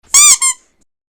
squeak2.wav